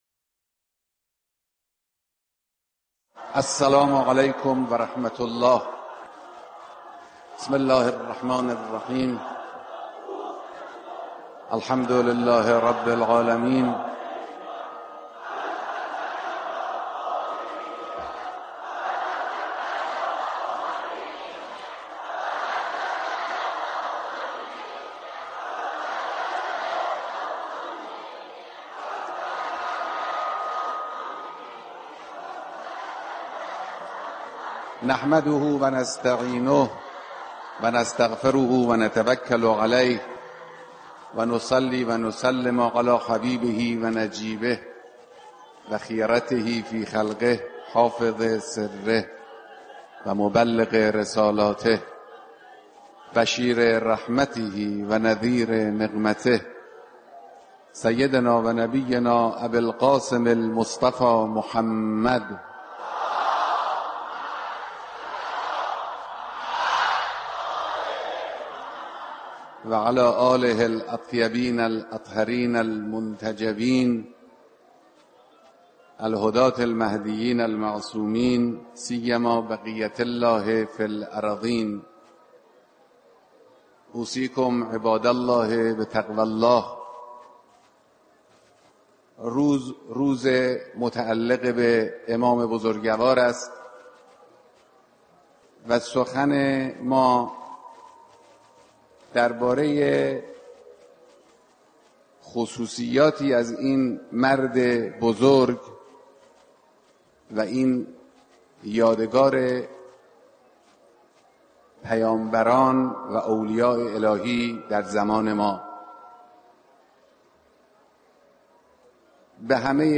خطبه‌های نمازجمعه‌